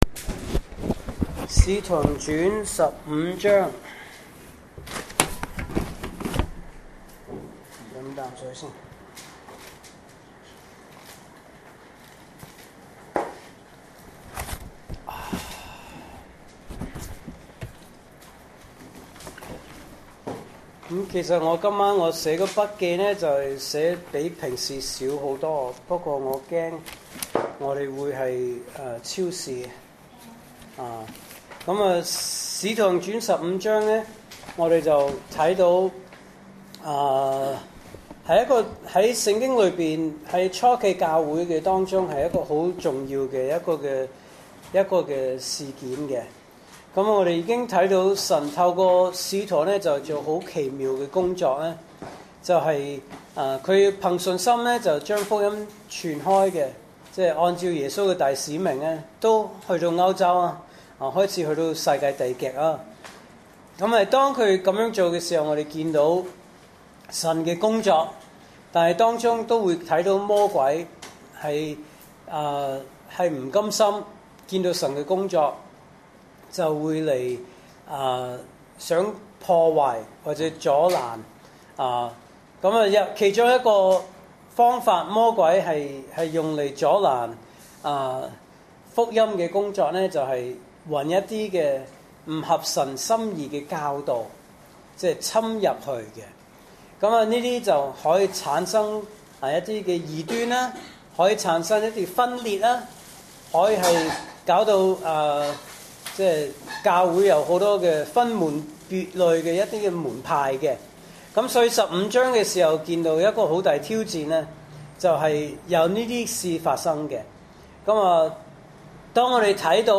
來自講道系列 "查經班：使徒行傳"